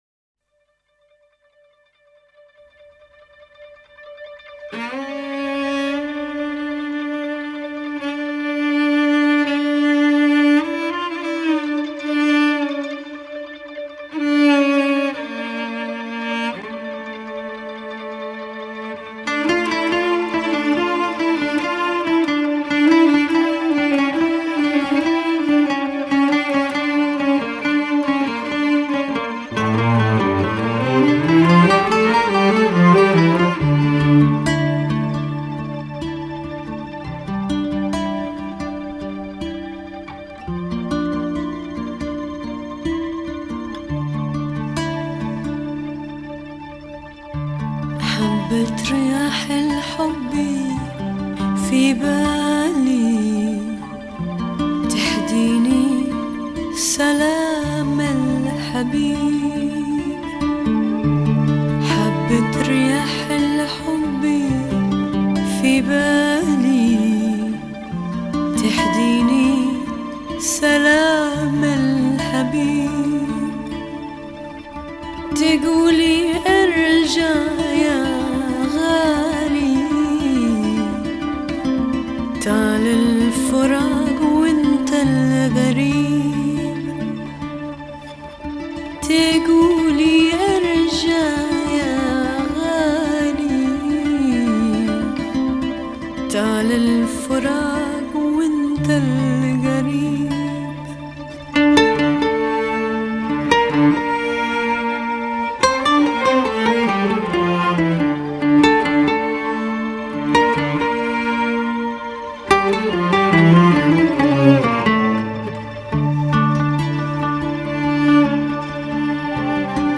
اغنية عربية